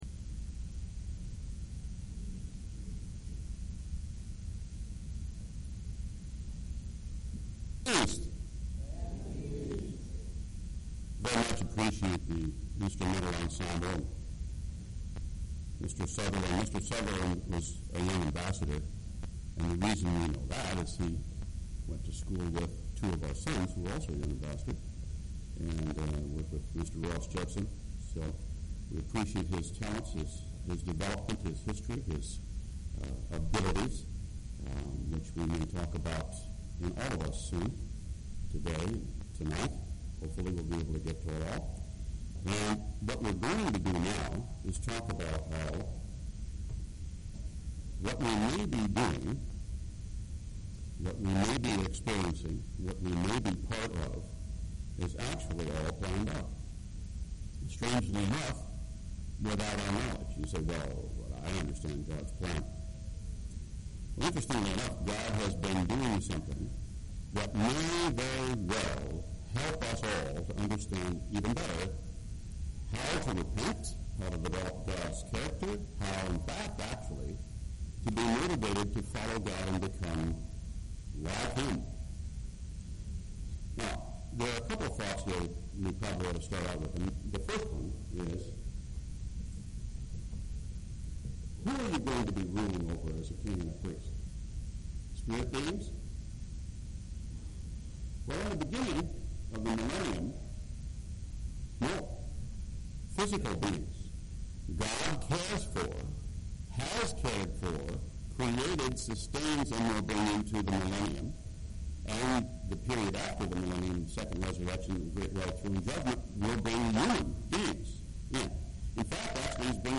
This sermon was given at the Oconomowoc, Wisconsin 2016 Feast site.